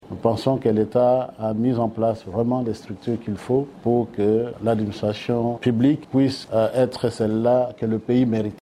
Suivez un extrait du discours de Christophe Bitasimwa.